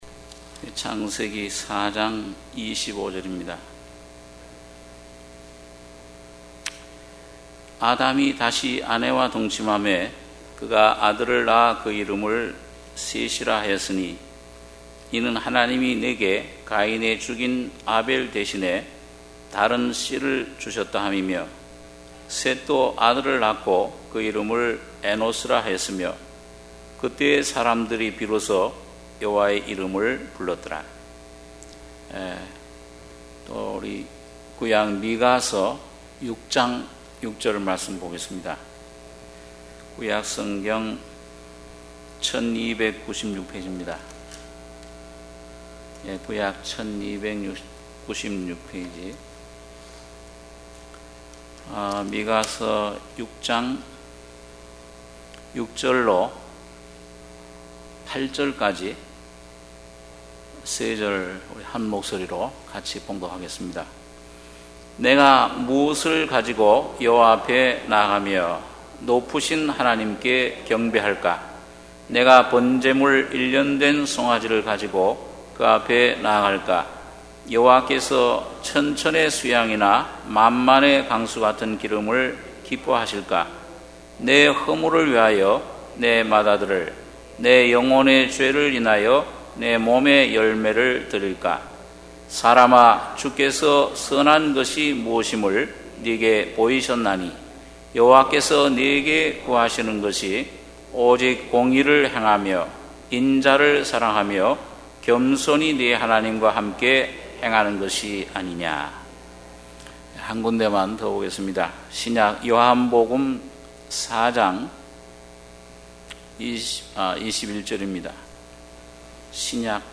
특별집회 - 창세기 4장 25절~26절